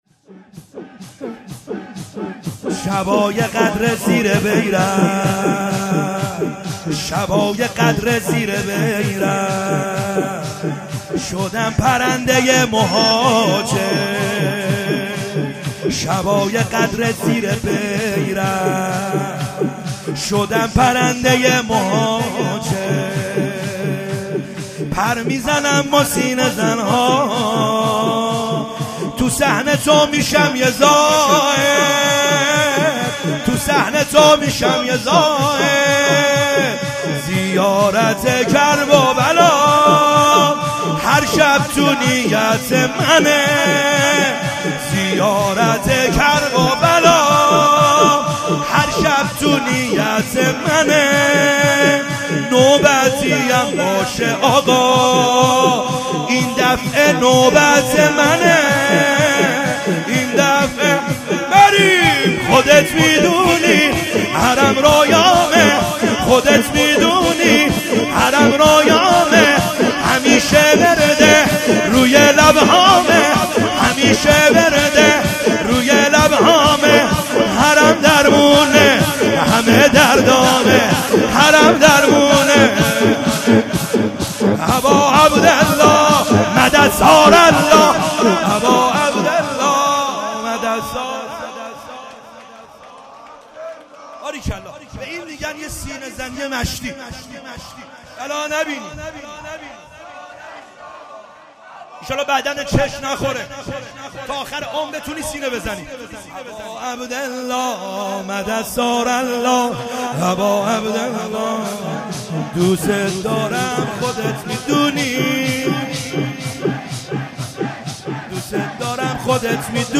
خیمه گاه - بیرق معظم محبین حضرت صاحب الزمان(عج) - شور | شبای قدر زیر بیرق